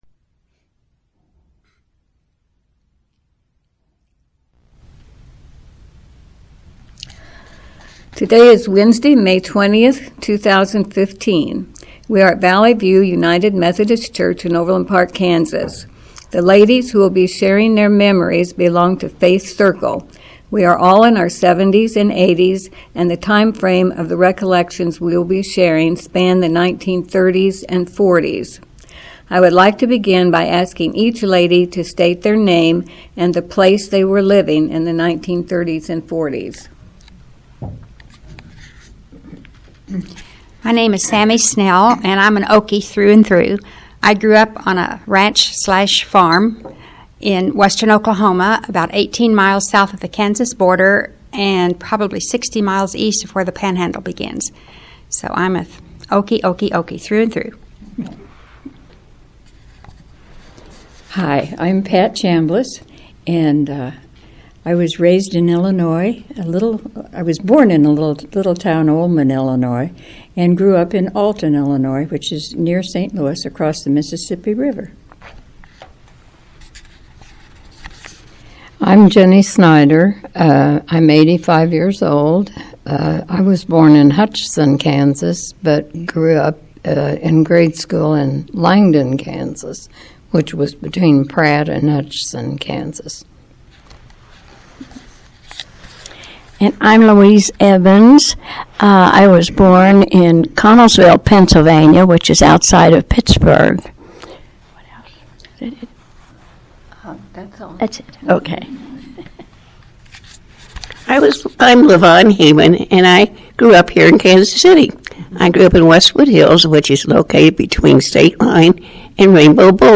In this interview six women, ages 70 to 80, share their childhood memories of the telephone, radio, movies and music.